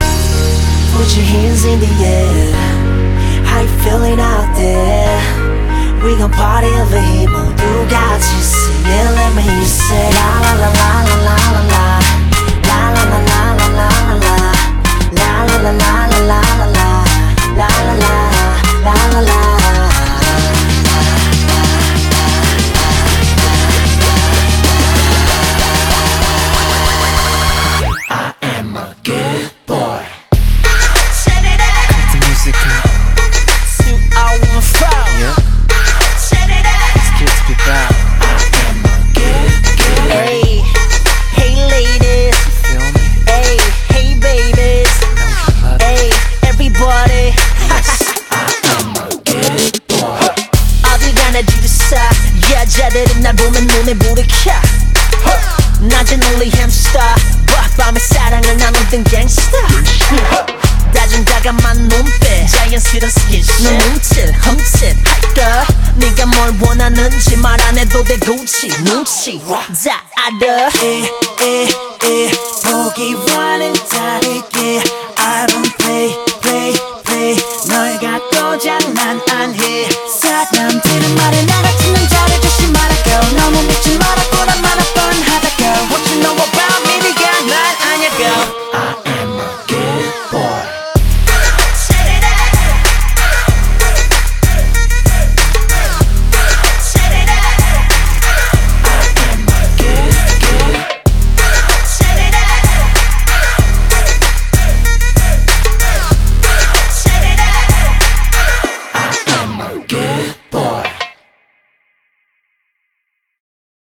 BPM95
MP3 QualityMusic Cut